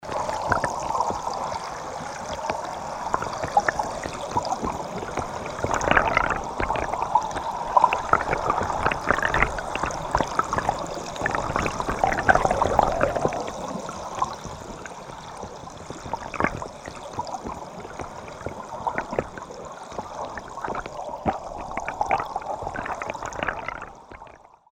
Образец записи с гидрофона Соната-У
Запись сделана с помощью портативного рекордера Zoom H5.